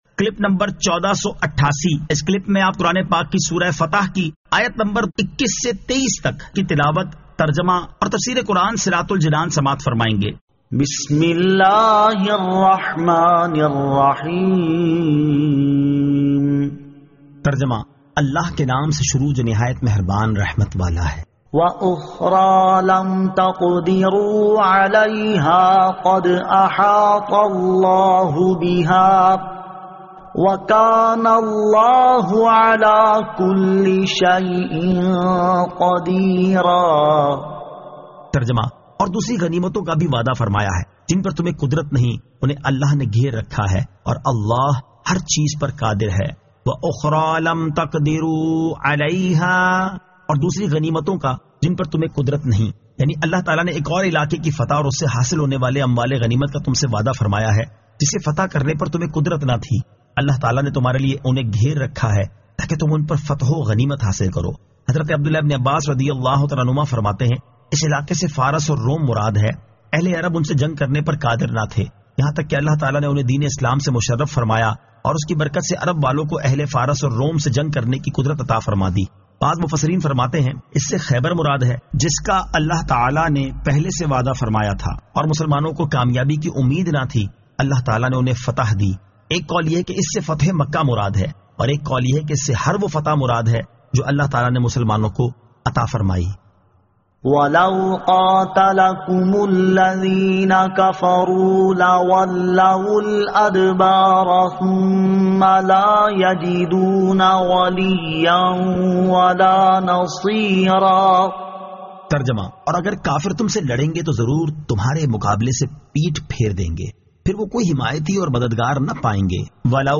Surah Al-Fath 21 To 23 Tilawat , Tarjama , Tafseer
2024 MP3 MP4 MP4 Share سُوَّرۃُ الفَتَحِ آیت 21 تا 23 تلاوت ، ترجمہ ، تفسیر ۔